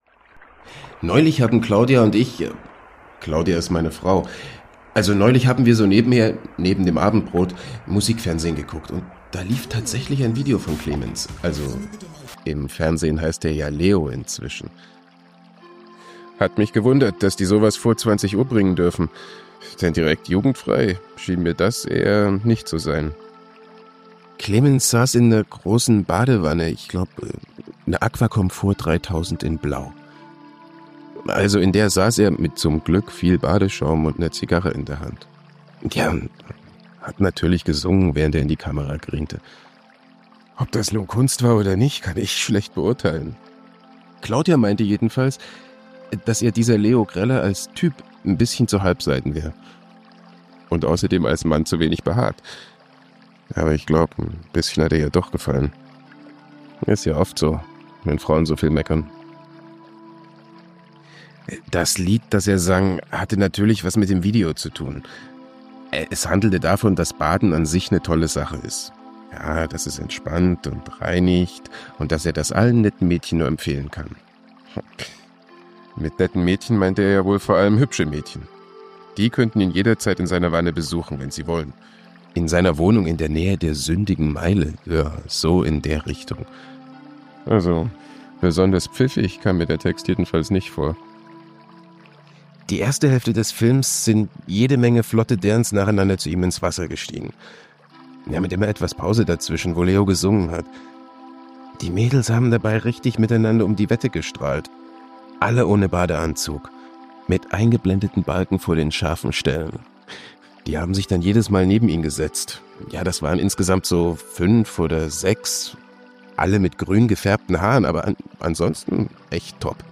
legendaeres-froschfrau-video-mit-tom-wlaschiha-als-erzähler.mp3